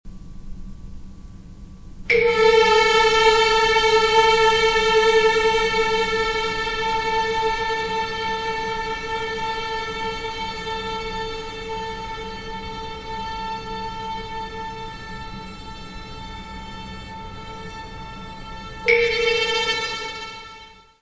flautas
Área:  Música Clássica
concerto per flauti ed orchestra.